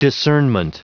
Prononciation du mot discernment en anglais (fichier audio)
Prononciation du mot : discernment